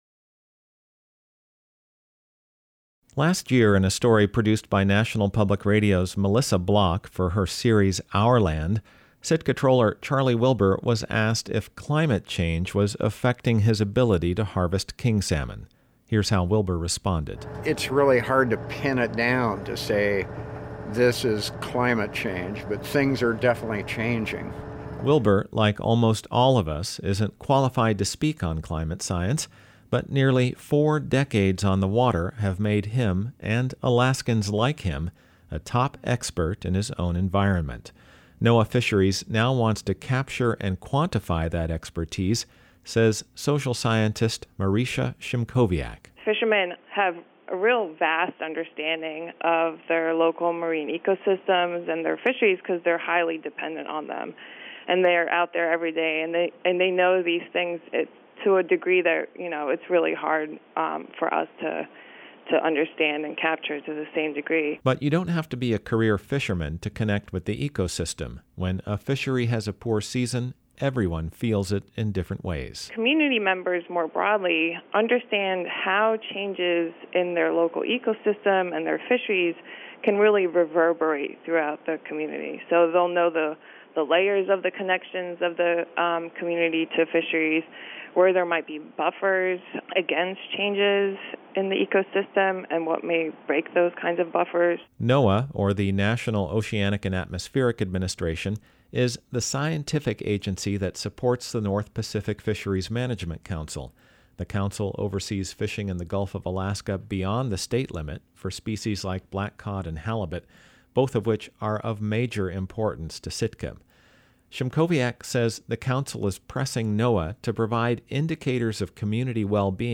Last year in a story produced by National Public Radio’s Melissa Block for her series Our Land